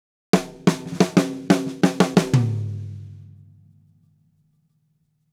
Drumset Fill 16.wav